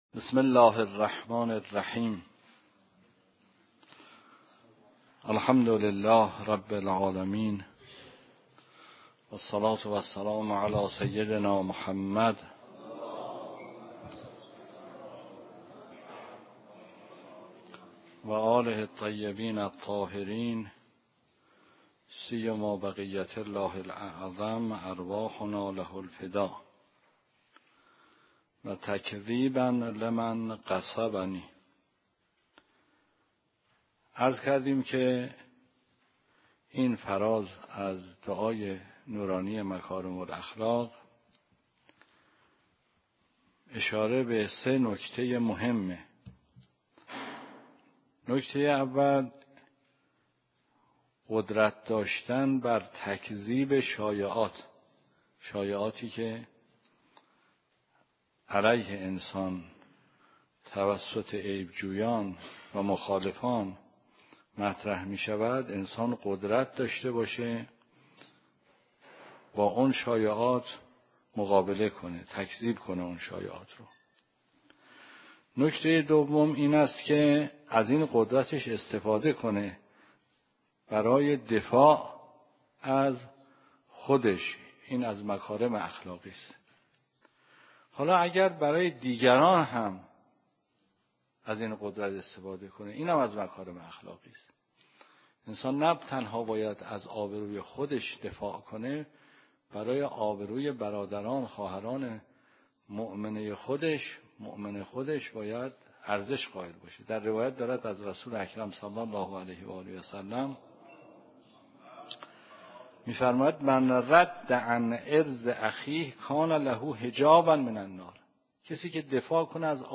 درس خارج فقه مبحث حج